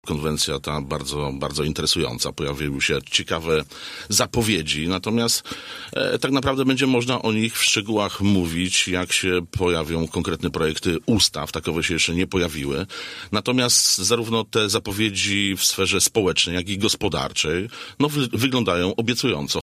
Poseł koła Wolni i Solidarności komentował w Radiu Zachód sobotnią konwencję partii rządzącej.